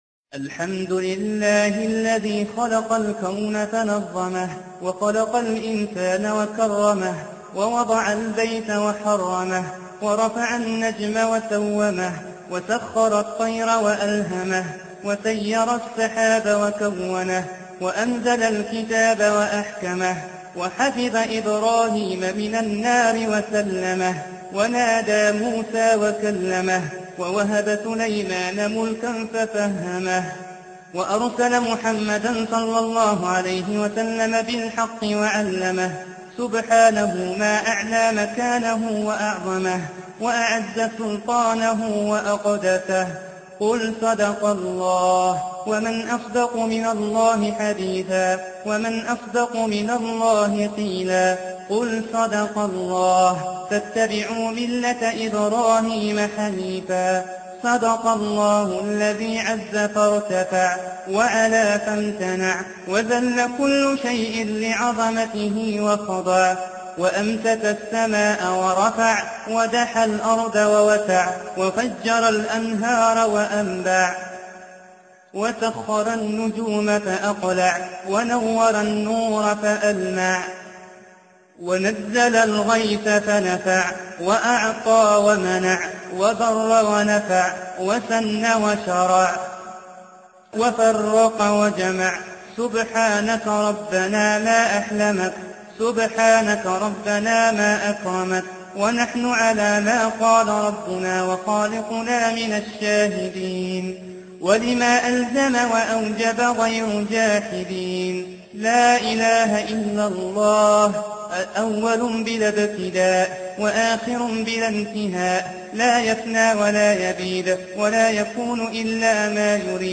دعاء ختم القرآن